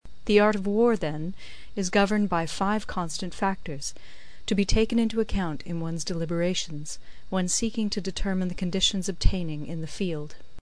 有声读物《孙子兵法》第2期:第一章 始计(2) 听力文件下载—在线英语听力室